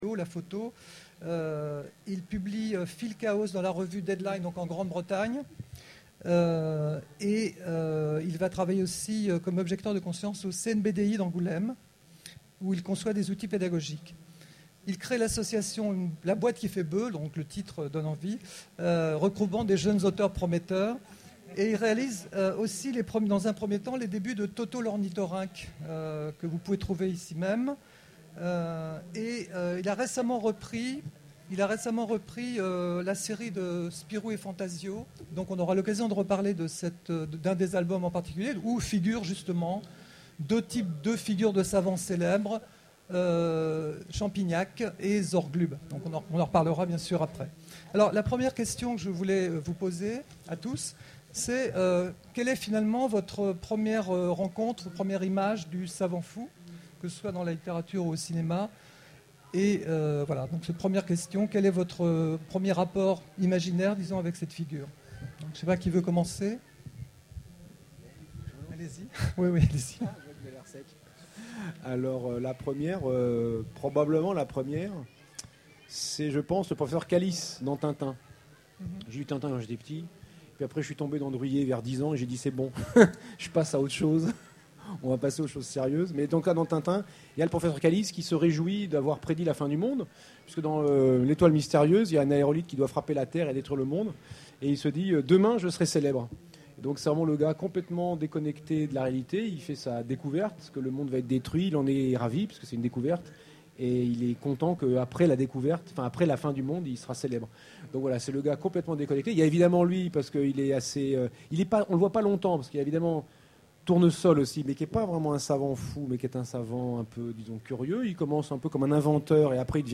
Utopiales 12 : Conférence Savants fous versus scientifiques dans la science-fiction